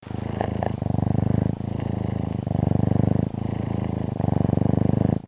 purr.mp3